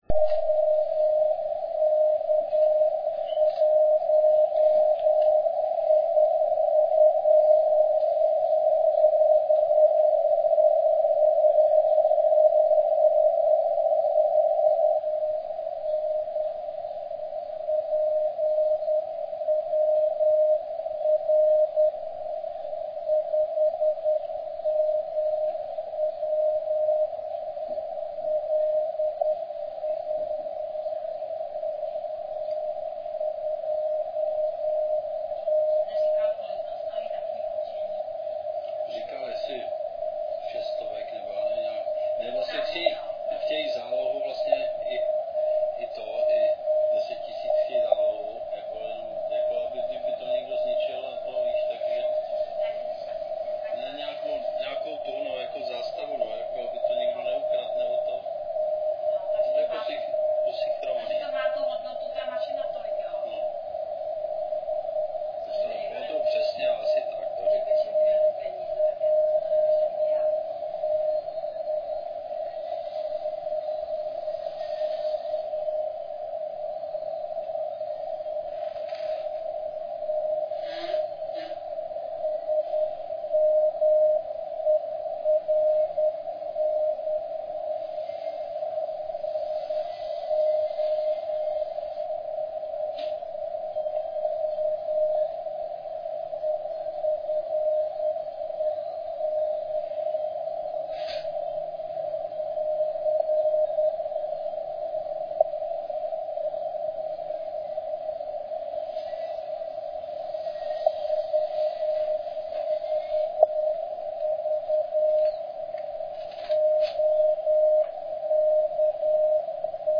9.30 SEC (*.mp3 300KB)  Zde si všimněte, jak se začíná rozvíjet Es vrstva, všimněte si QSB